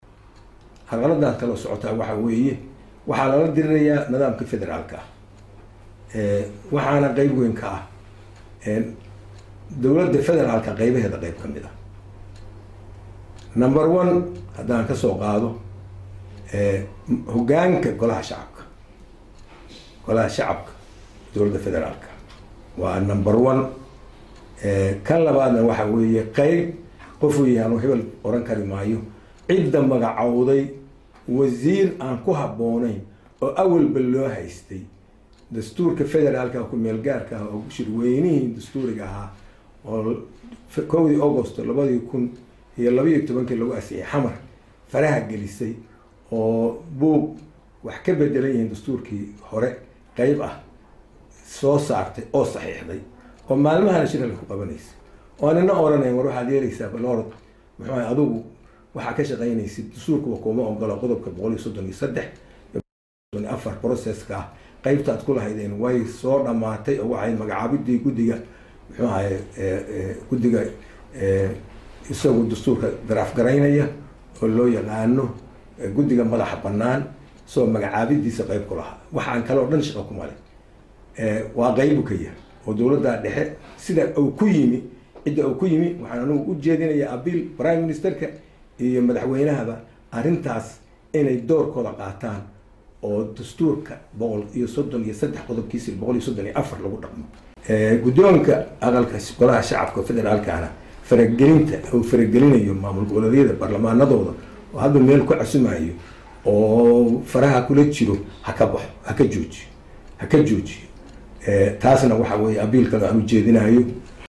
Xildhibaan Cabdiraxmaan Sheekh Maxamed Maxamuud(Faroole) oo ka tirsan Aqalka sare ee Baarlamanka Soomaaliya, oo warbaahinta kula hadlay Magaalada Garowe ayaa ka hadlay arrimo badan oo ku saabsan Xaaladda Soomaaliya.